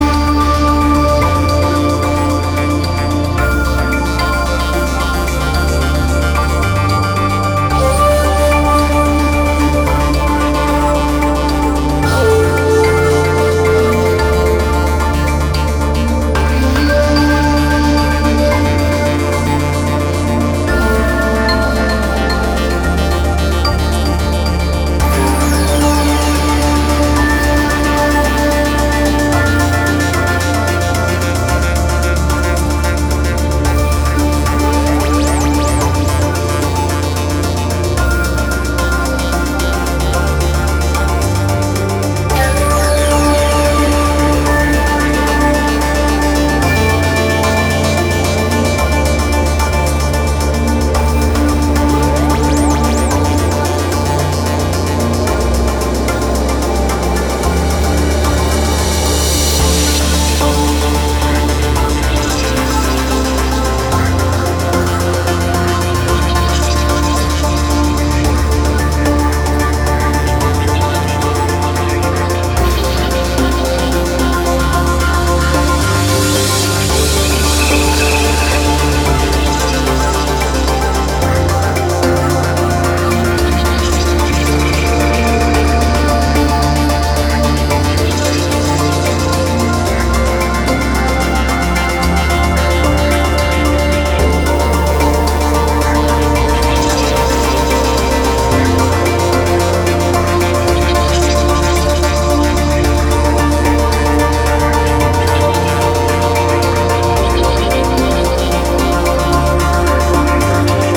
an EP full of light and energy
With a bass-line begging for movement